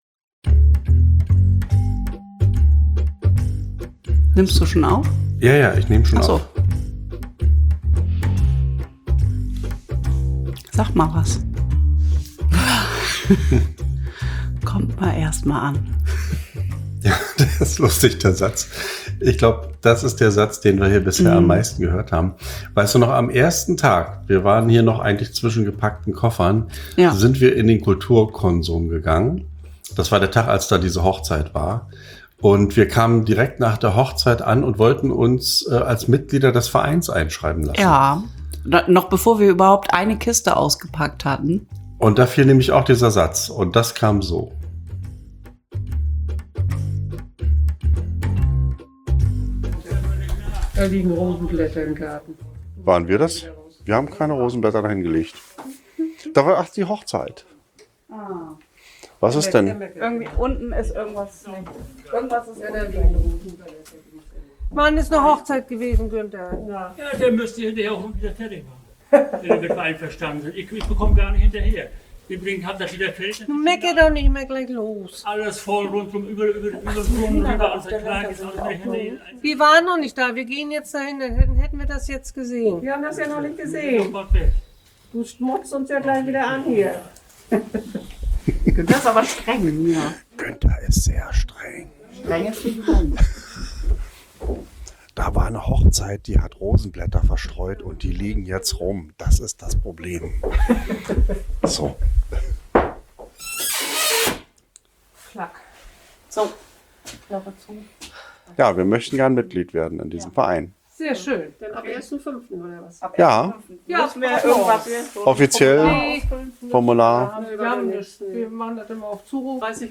Wir sind ein Ehepaar.